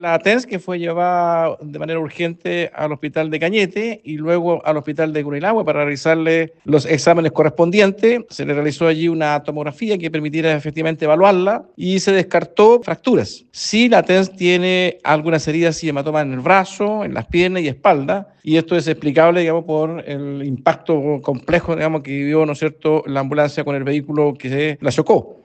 El delegado de la provincia de Arauco, Humberto Toro, informó que la profesional fue llevada primero al Hospital de Cañete y posteriormente al de Curanilahue, donde se le practicó una tomografía y se descartó alguna fractura.